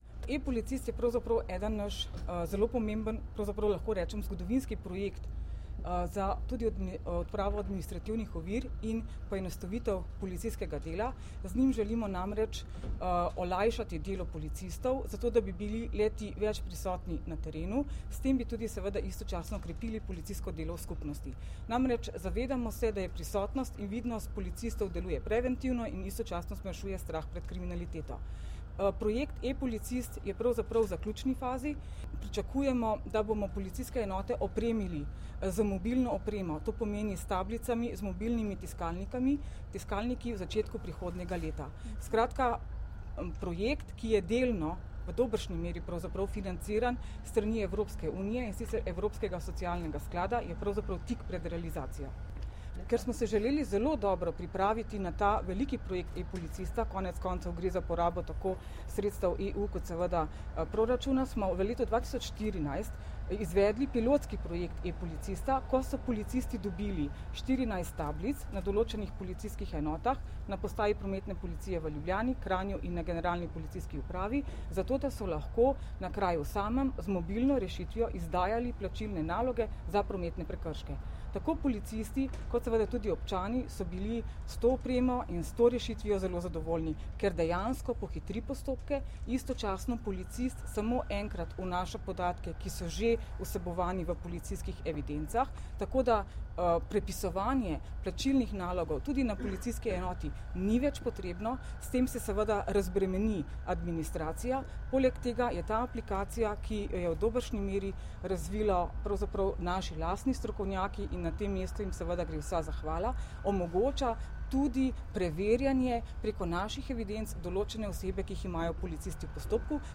Zvočni posnetek izjave mag. Tatjane Bobnar (mp3)